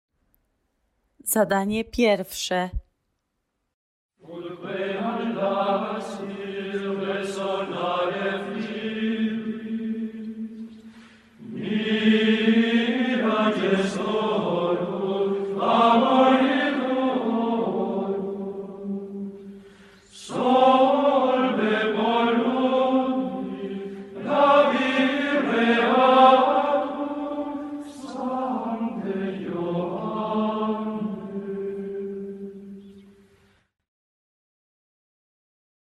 Zapoznaj się z zapisem nutowym oraz z fragmentem nagrania hymnu do św. Jana Chrzciciela Ut queant laxis, który jest związany z metodą nauki śpiewu stworzoną w XI wieku przez jednego z czołowych teoretyków epoki średniowiecza.